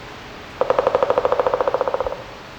Sounds from the IBM Glen
Pileated Woodpecker (drumming).wav